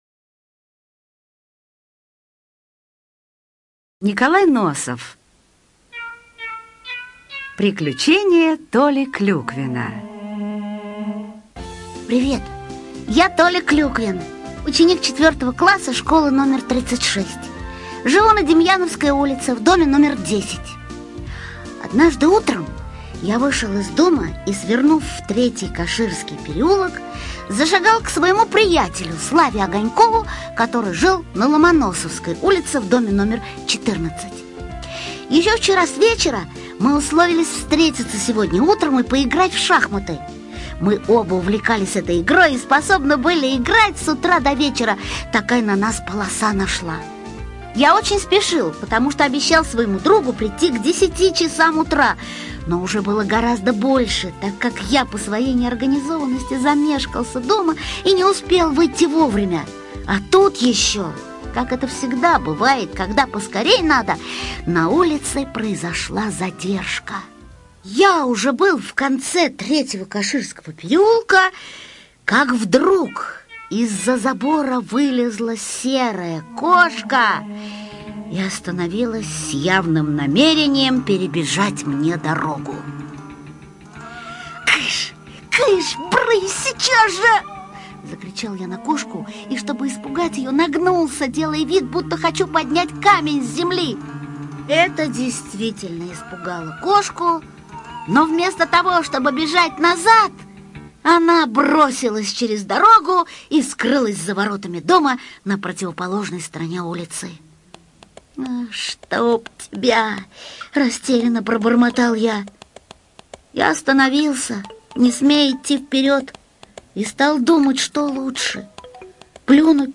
Слушайте Приключения Толи Клюквина - аудиосказку Носова Н.Н. Толя Клюквин пошел в гости к своему другу, но перед ним пробежала черная кошка.